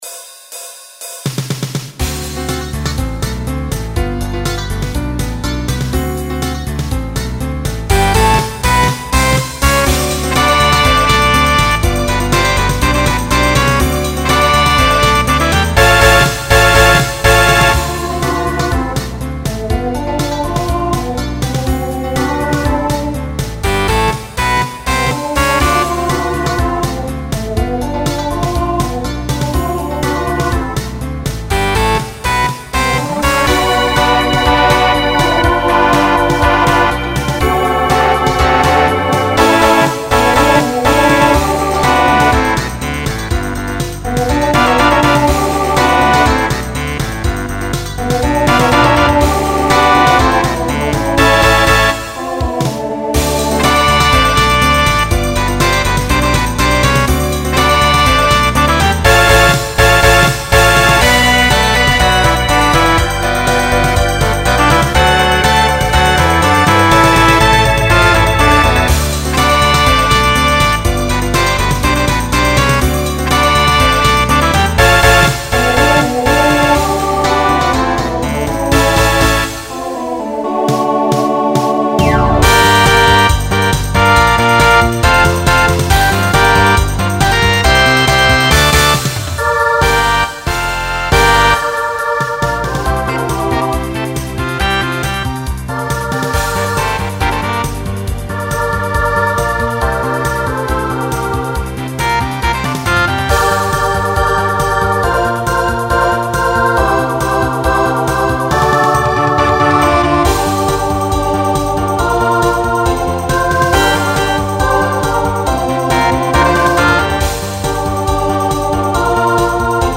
Genre Rock Instrumental combo
Voicing SSA